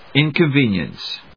音節in・con・ve・nience 発音記号・読み方
/ìnkənvíːnjəns(米国英語), ˌɪnkʌˈnvi:njʌns(英国英語)/